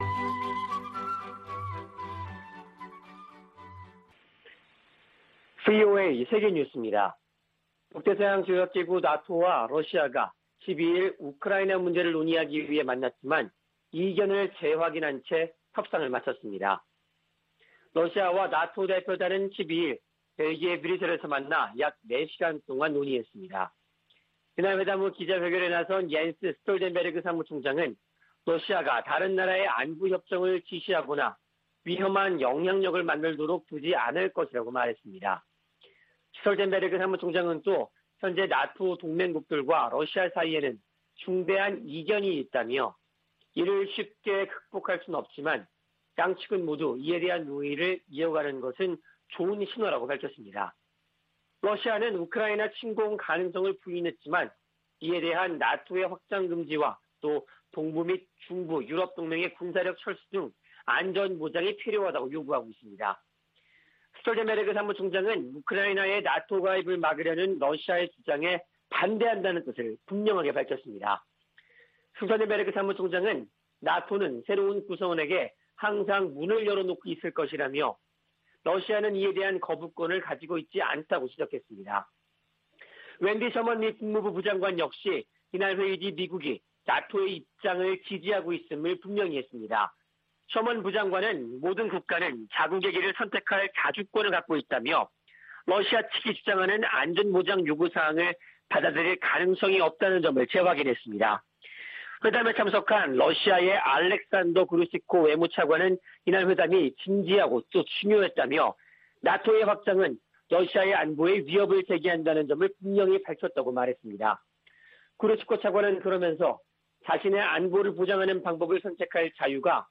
VOA 한국어 아침 뉴스 프로그램 '워싱턴 뉴스 광장' 2021년 1월 13일 방송입니다. 북한은 11일 쏜 발사체가 극초음속 미사일이었고 최종 시험에 성공했다고 발표했습니다. 백악관이 북한의 최근 미사일 발사를 규탄하면서 추가 도발 자제와 대화를 촉구했습니다. 유엔 사무총장은 북한의 연이은 미사일을 발사를 매우 우려하고 있다고 밝혔습니다.